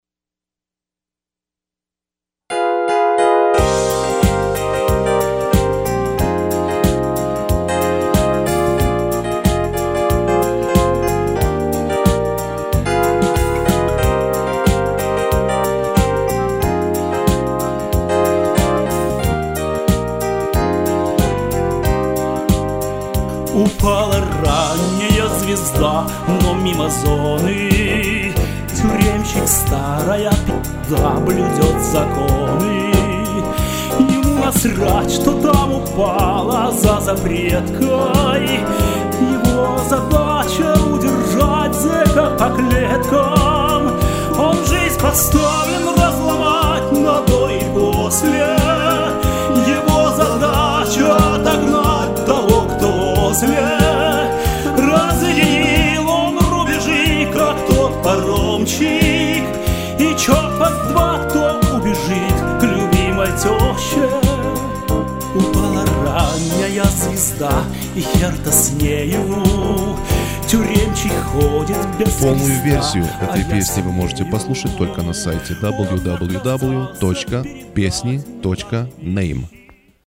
• Исполняет: Cccp-2
• Жанр: Блюз